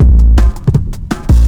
.wav 16 bit 44khz, Microsoft ADPCM compressed, mono,
LMgrumpy.wav Breakbeat 33k